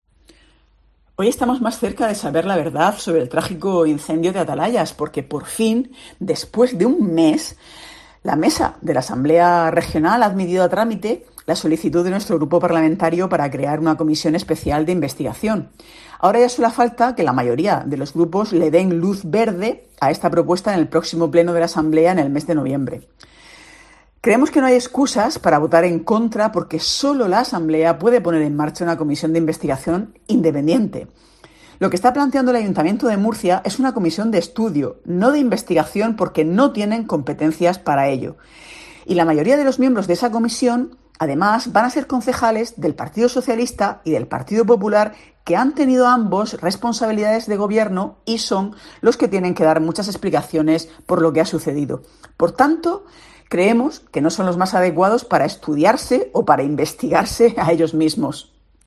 María Marín, portavoz de Podemos-IU-AV